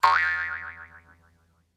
Tono de llamada Sonido de Boeing
Categoría Notificaciones